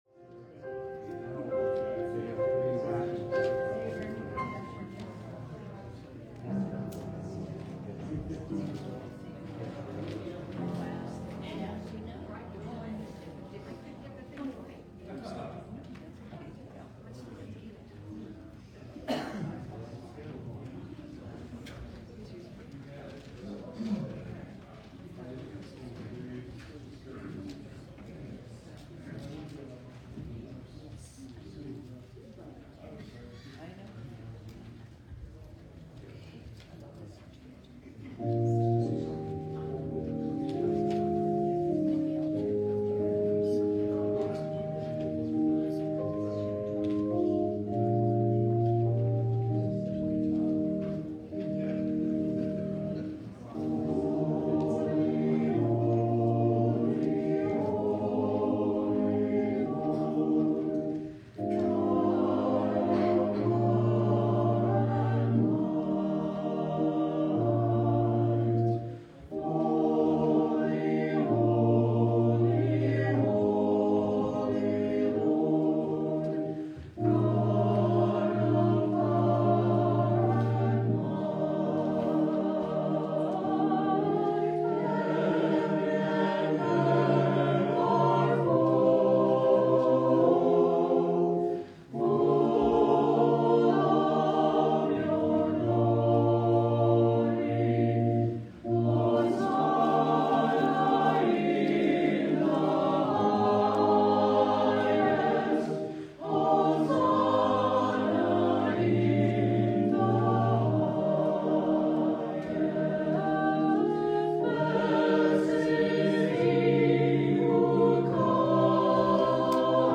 Sermons | Covenant Presbyterian DTC